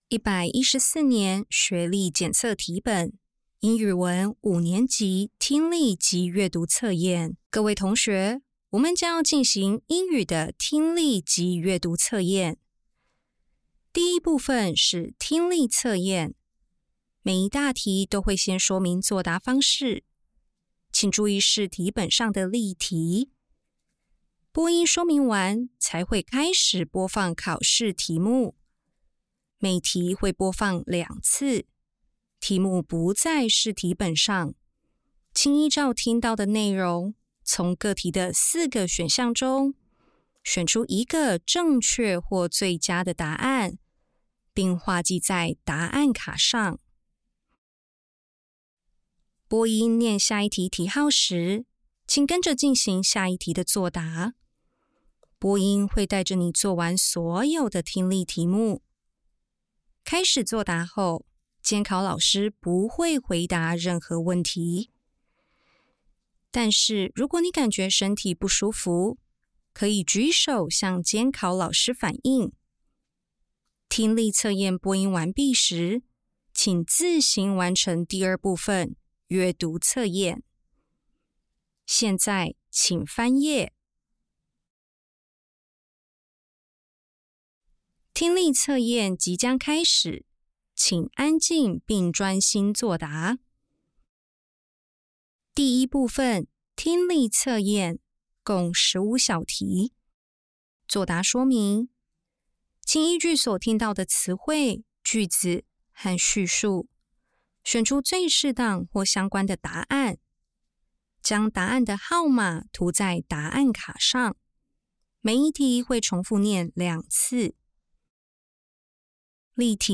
114年縣市學生學習能力檢測五年級英語科＿英聽檔.mp3